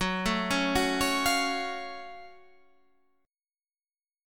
GbmM7 chord